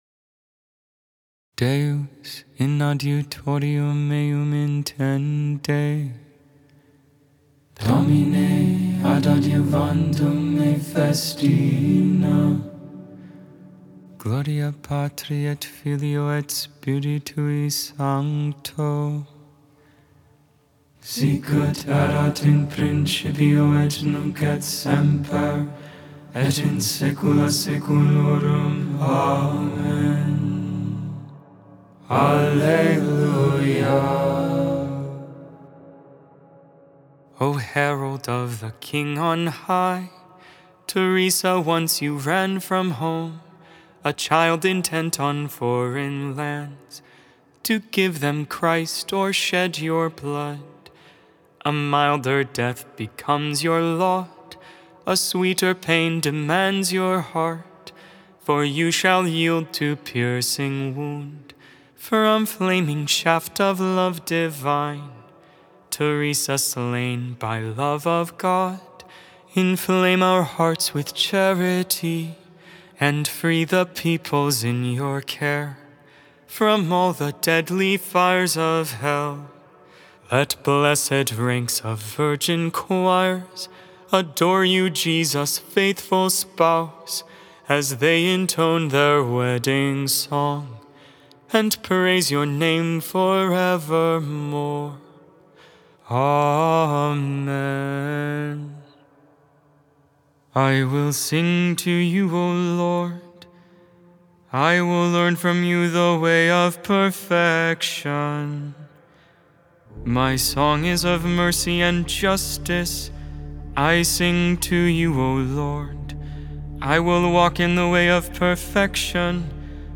Gregorian tone 6
Gregorian tone 3
(StH arrangement)The Canticle of Zechariah, Luke 1v68-79 (Latin, Gregorian tone 8)IntercessionsThe Lord's PrayerCollect (Concluding Prayers)